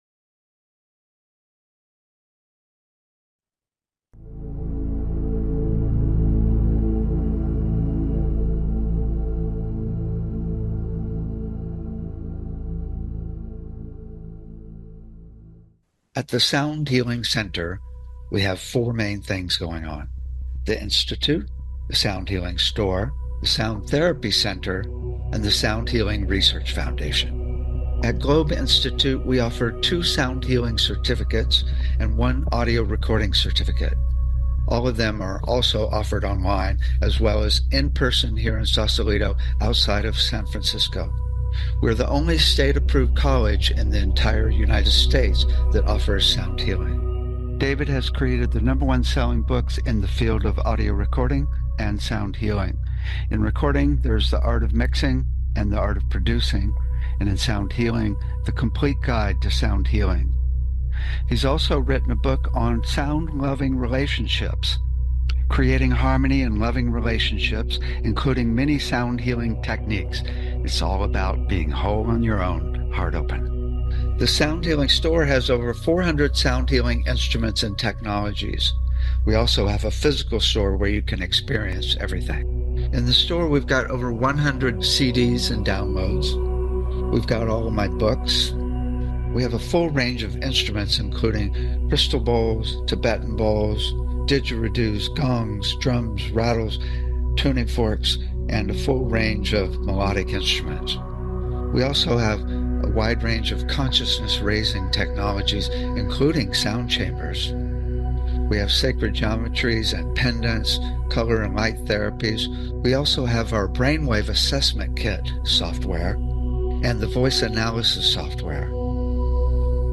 Talk Show Episode, Audio Podcast, Sound Healing and The Soul, the Notes, Frequency, Tamper, and Song of Your Soul on , show guests , about The Soul,Frequency of Soul,Song of Your Soul,the Sound Healing Center,Soul as a Stable Frequency,Power of the Soul Song,The Bridge to Alignment,Ultimate Soul Expression, categorized as Health & Lifestyle,Energy Healing,Sound Healing,Emotional Health and Freedom,Science,Self Help,Society and Culture,Spiritual,Meditation